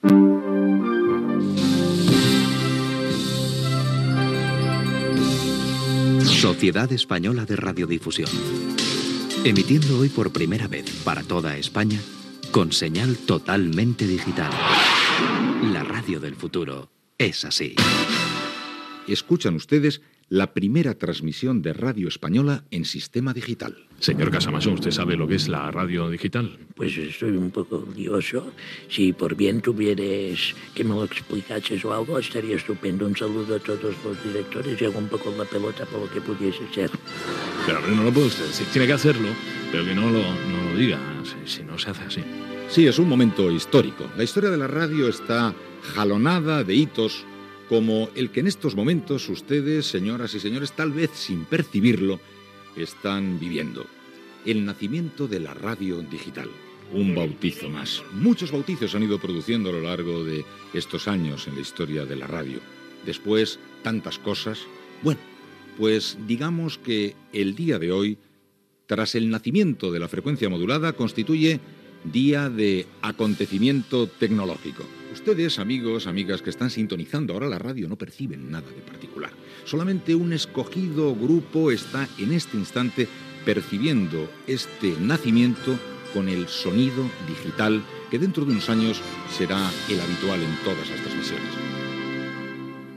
Indicatiu de la SER amb senyal totalment digital.
Info-entreteniment
L'enregistrament que podem escoltar es va emetre, per tant, a les bandes de ràdio analògiques.